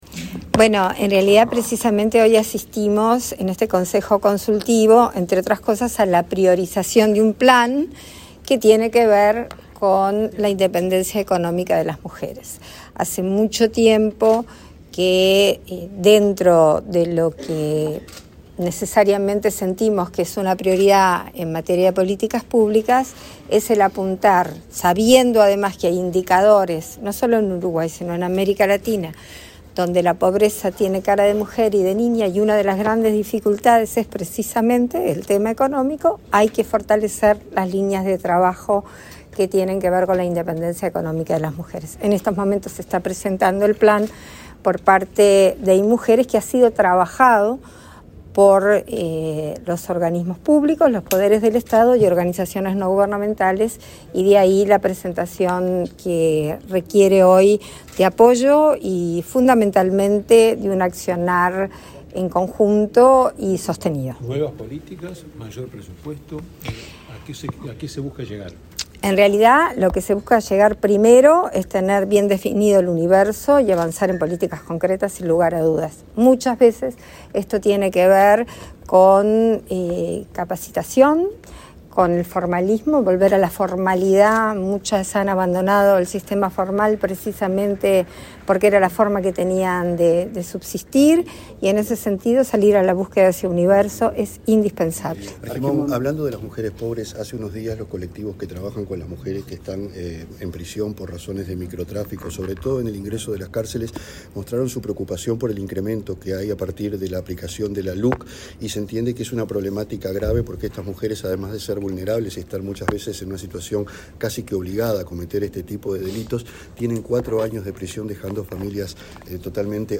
Declaraciones de la presidenta en ejercicio, Beatriz Argimón, a la prensa
Este viernes 28, la presidenta en ejercicio, Beatriz Argimón, participó, en la Torre Ejecutiva, en la reunión del Consejo Nacional de Género y luego